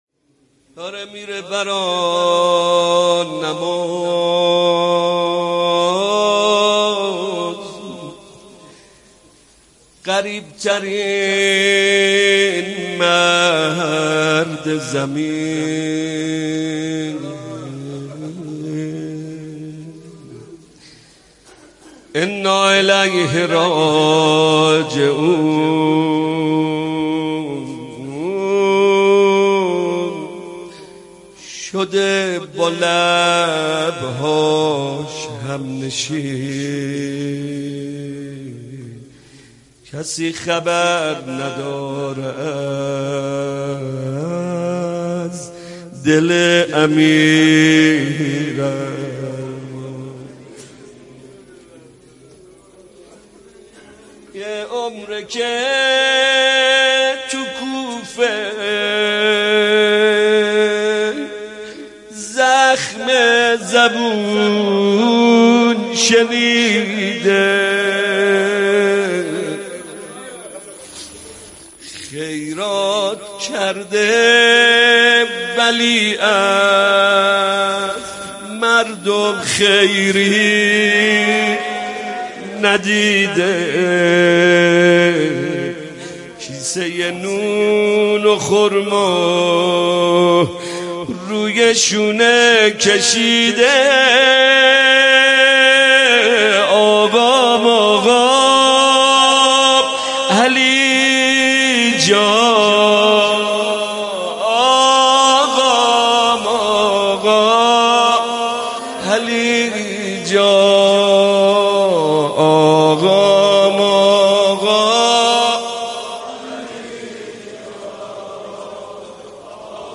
روضه شهادت امیرالمؤمنین(ع)